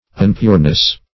-- Un*pure"ness , n. [1913 Webster]